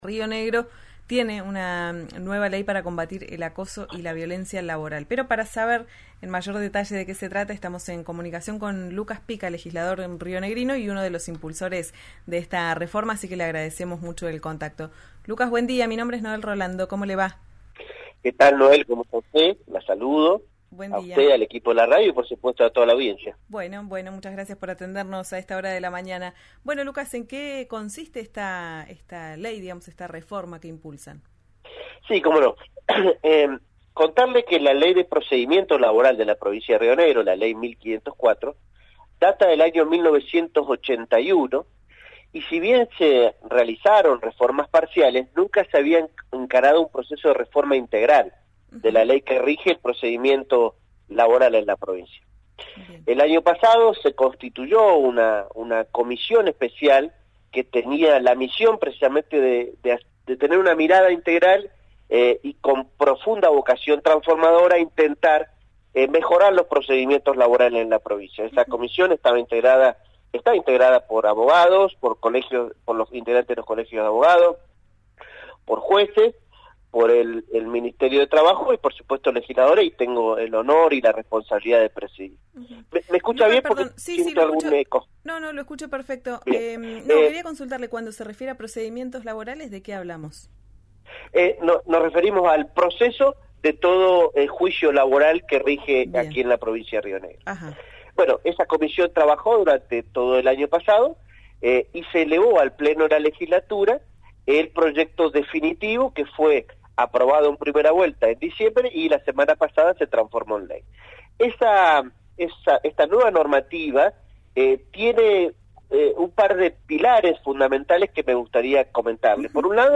Escuchá a Lucas Pica, diputado provincial, integrante del bloque Juntos Somos Rio Negro e impulsor de la medida, en RÍO NEGRO RADIO: